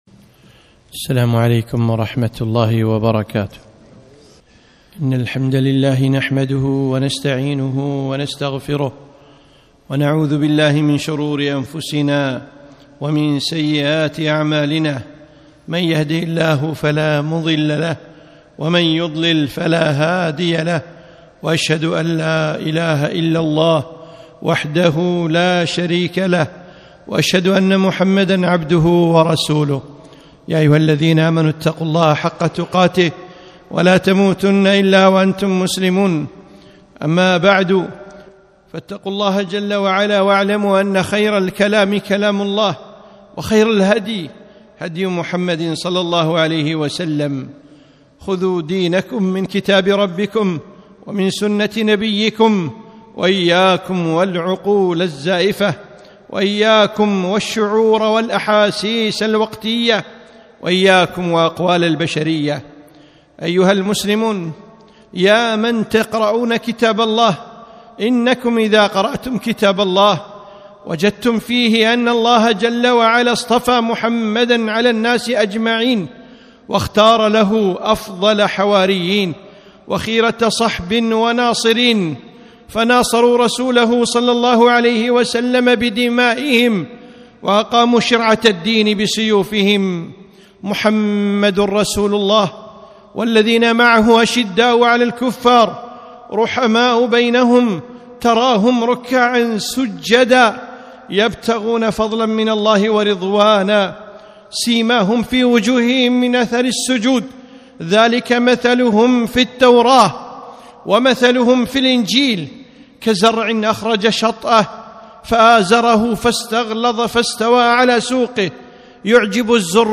خطبة - خير القرون - دروس الكويت